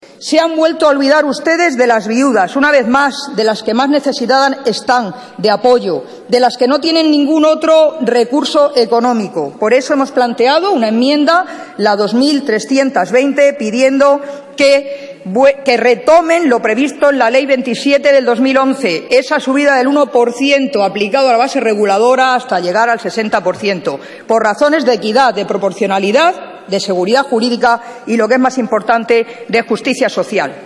Magdalena Valerio en el Congreso. debate de presupuestos 11/11/2013